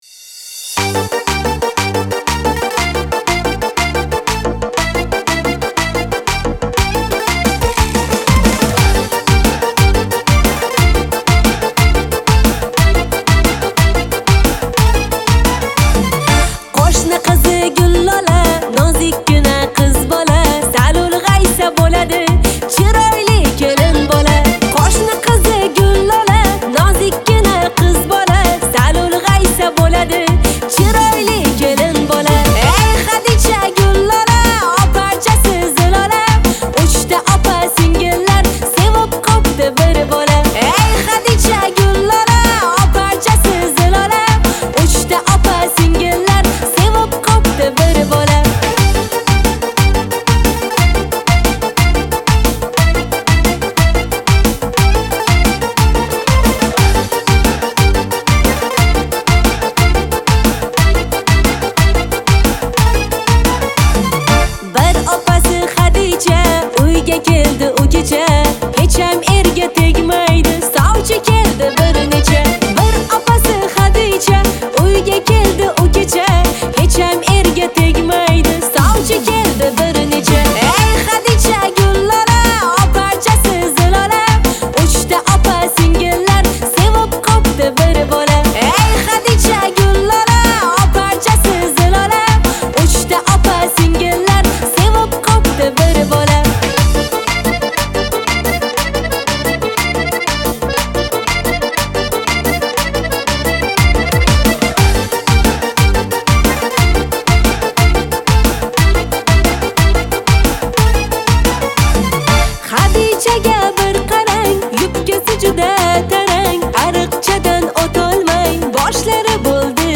• Жанр: New Uzb / Узбекские песни